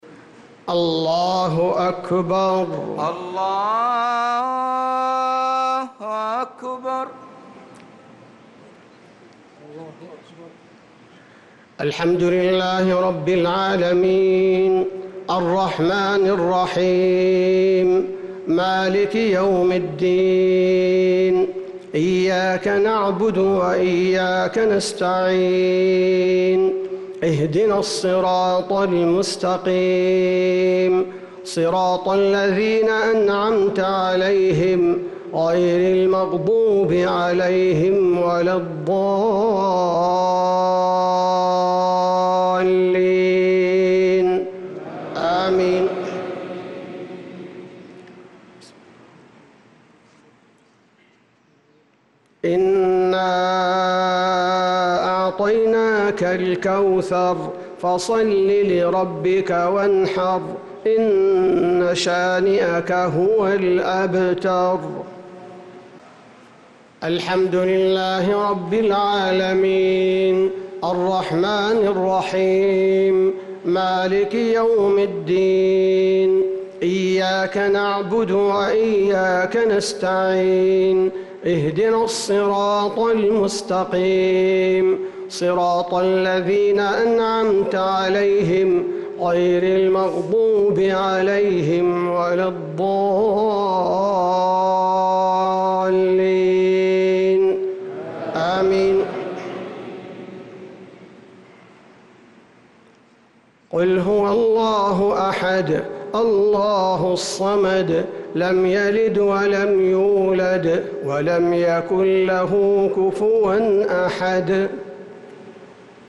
صلاة الجمعة ٣-٣-١٤٤٦هـ من سورتي الكوثر والإخلاص | Jumu’ah prayer from al-kawthar & Al-Ikhlaas 6-9-2024 > 1446 🕌 > الفروض - تلاوات الحرمين